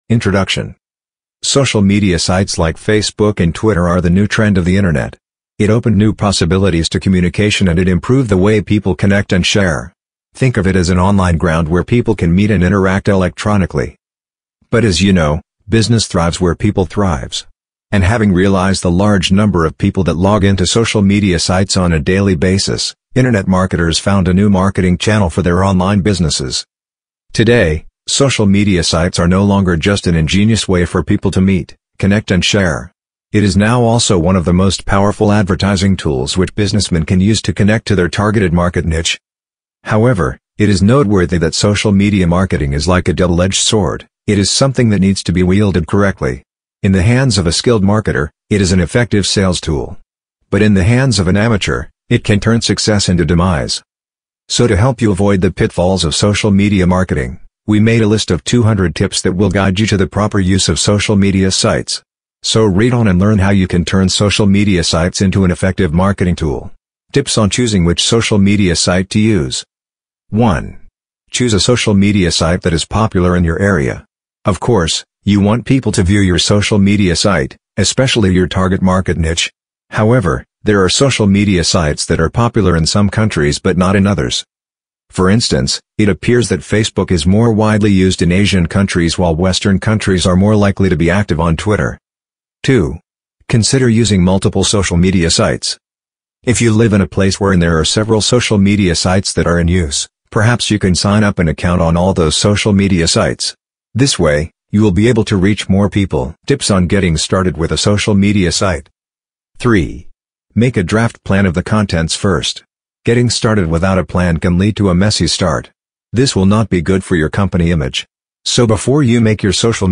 Featuring conversations with marketing leaders, CX experts, and entrepreneurs, the show uncovers what truly drives customer satisfaction and long-term relationships.